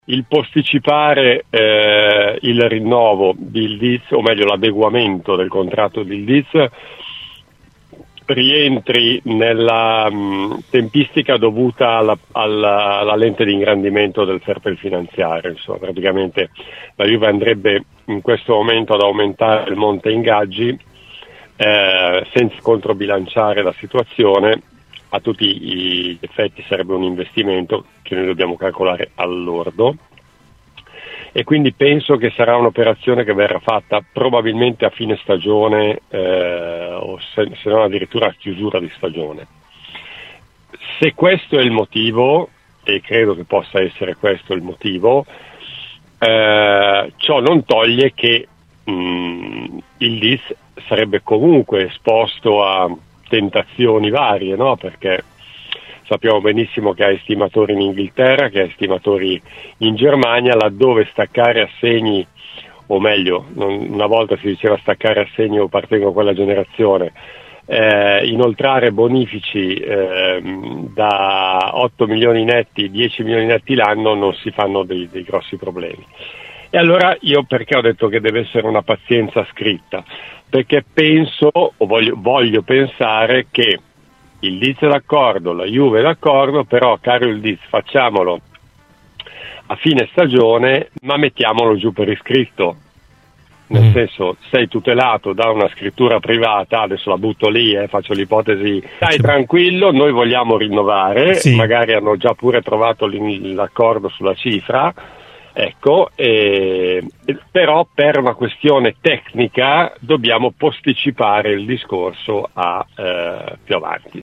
Ospite di “Cose di Calcio” su Radio Bianconera